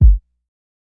KICK_SWIG.wav